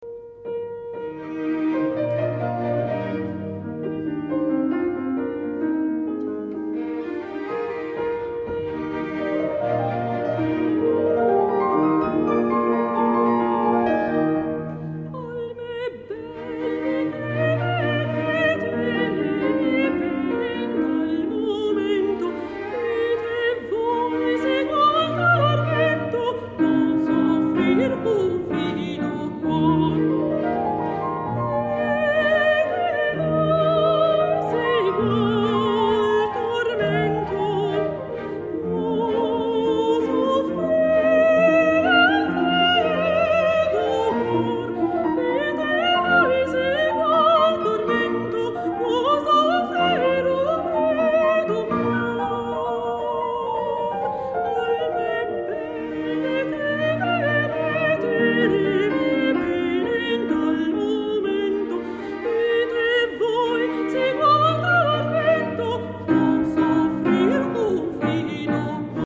Ensemble orchestrale giovanile - Registrazione dal vivo di uno dei concerti del Val della Torre Festival (Torino).